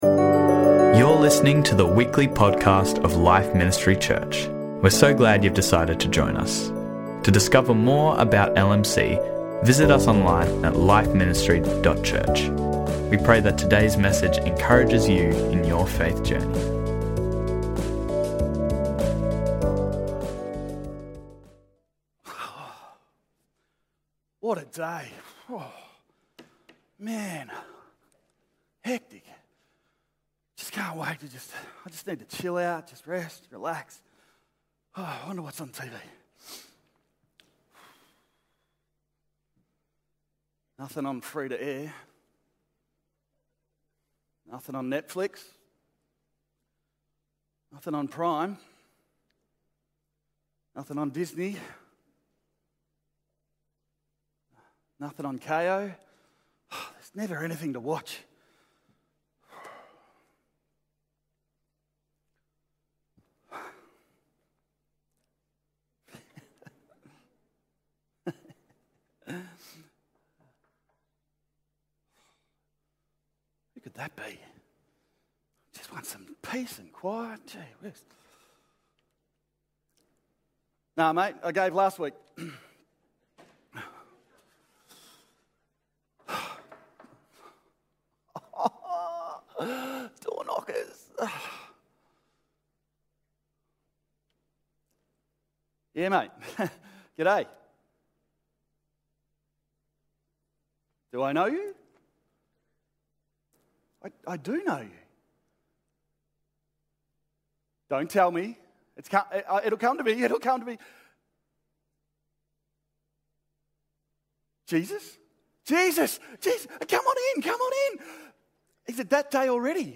The first 6 minutes of this message is a visual introduction.